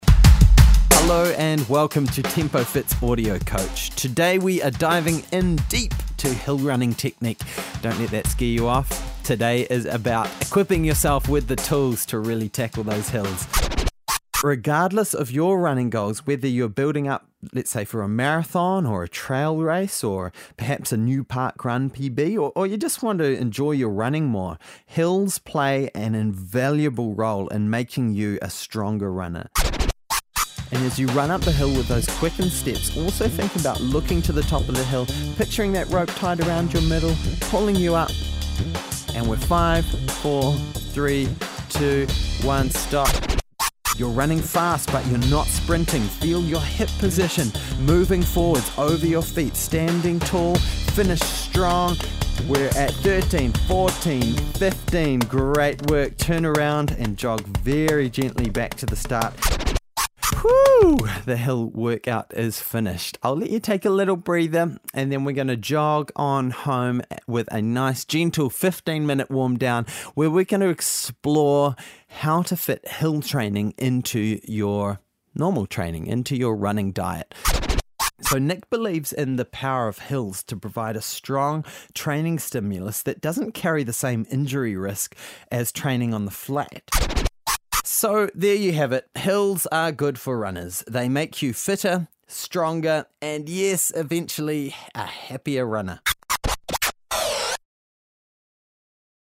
This guide is a listen-as-you-run audio tool.